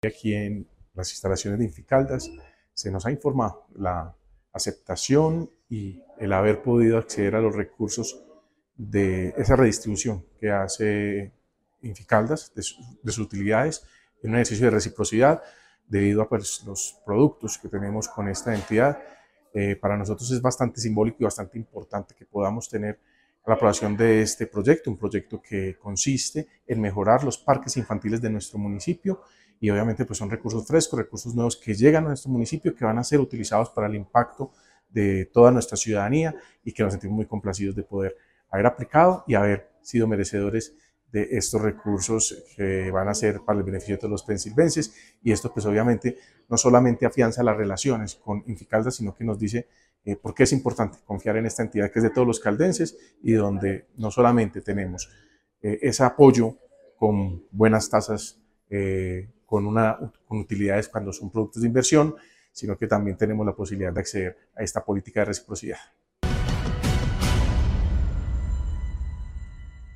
Jesús Iván Ospina, alcalde de Pensilvania